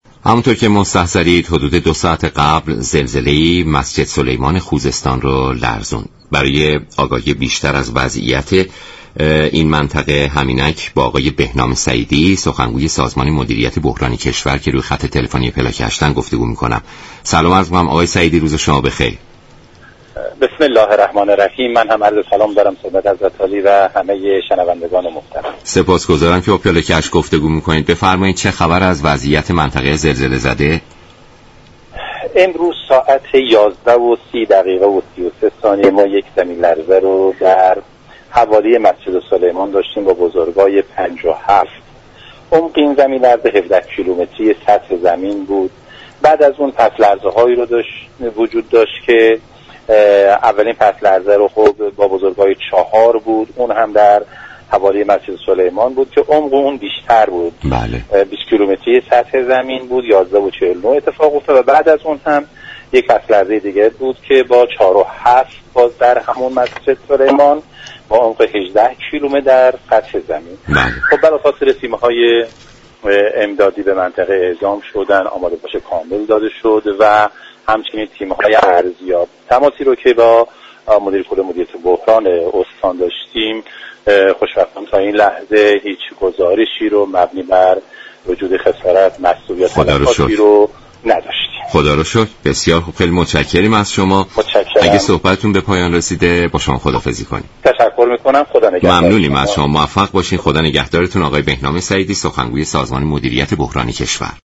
لازم به یادآوری است، این گفت و گو در ساعت 13:40 در رادیو ایران انجام شده است.